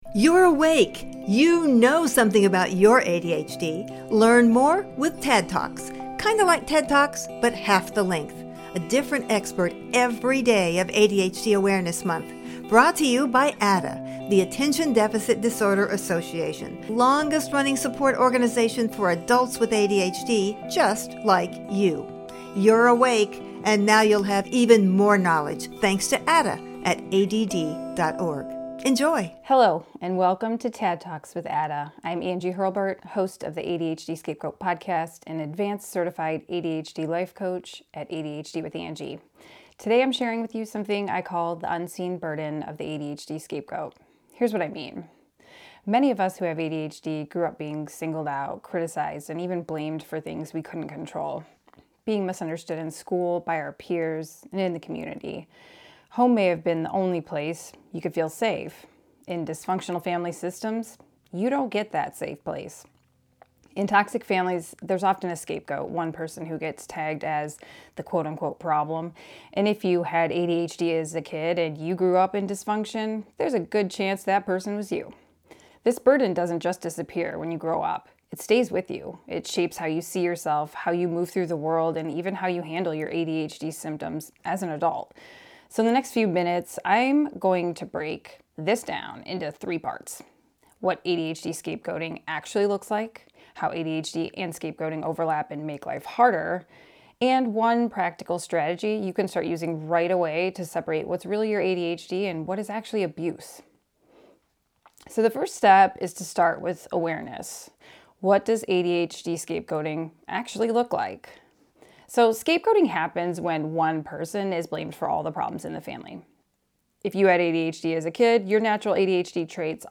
TADD TALK